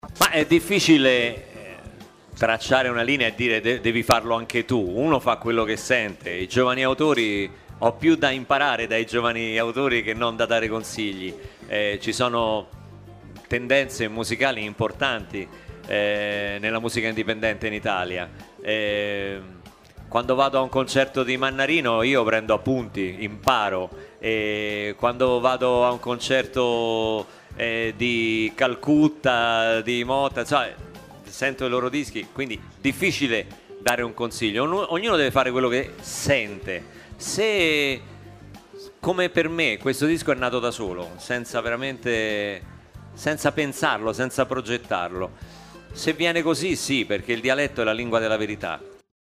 In sala stampa arriva Luca Barbarossa.